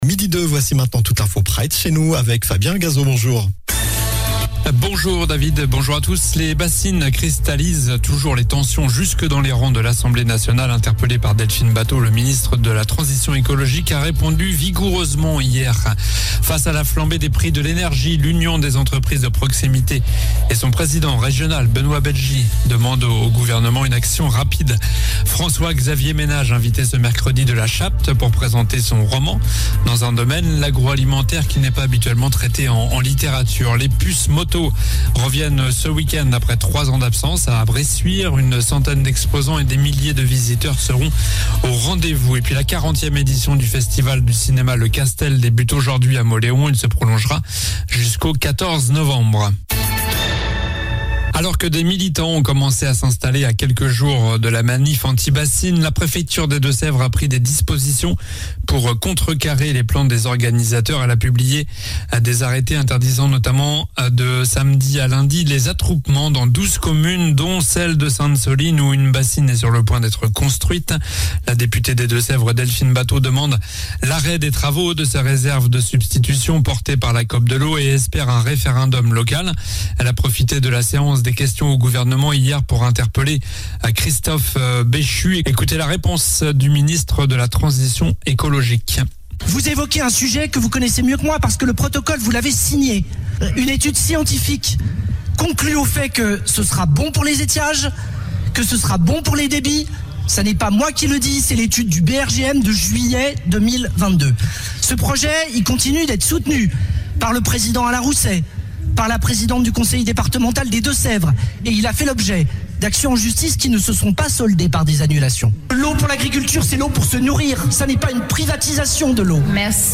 Journal du mercredi 26 octobre (midi)